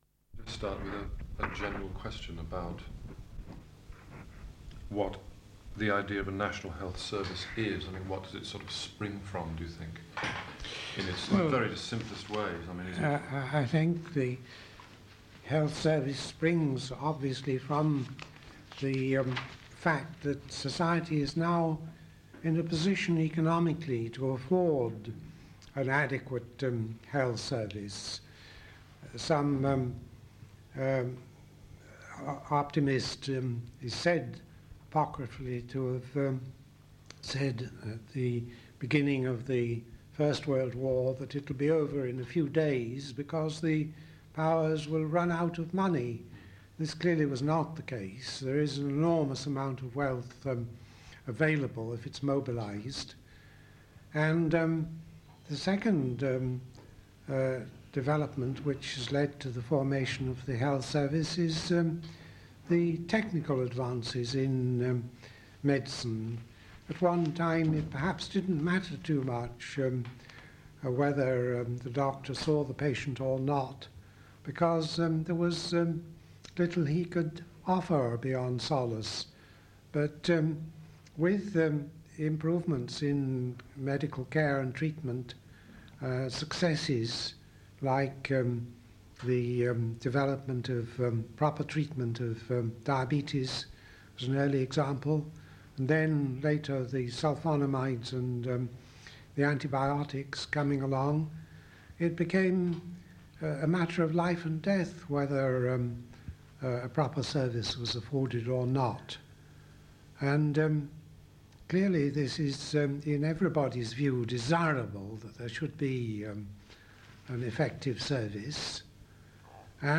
Both sides of the original audio cassette tape have been digitised.